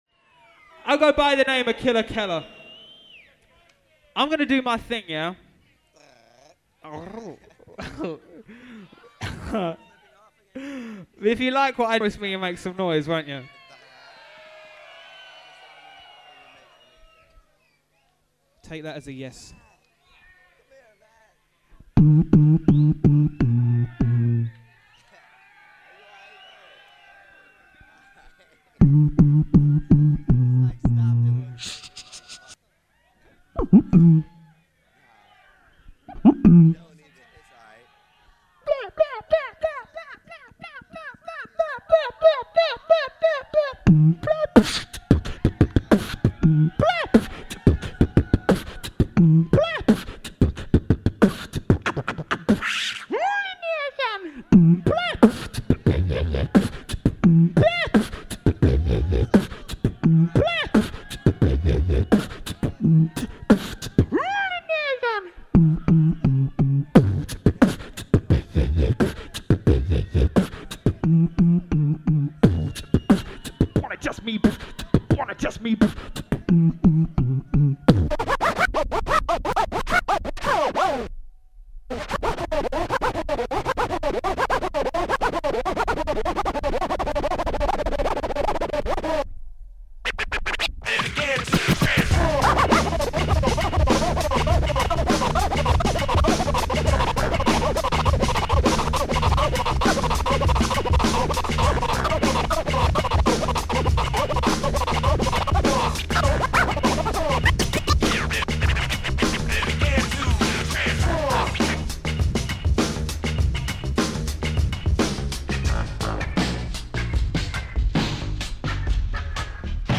Hip Hop italiano.